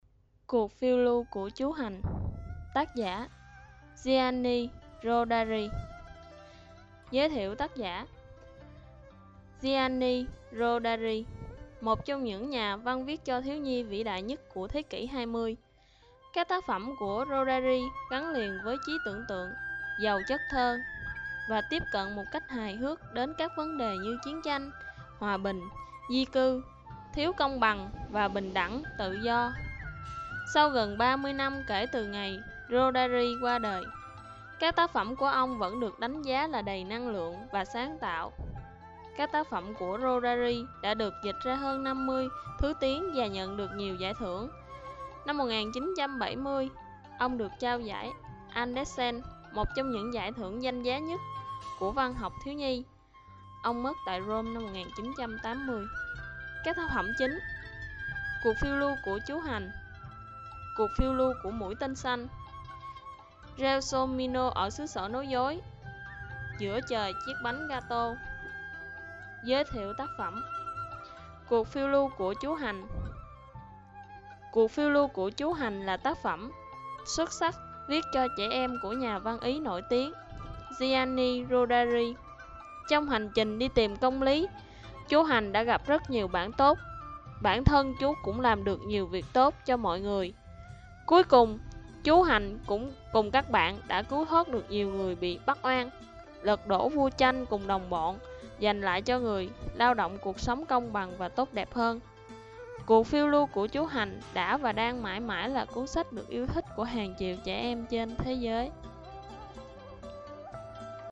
Sách nói | Cuộc phiêu lưu của Chú Hành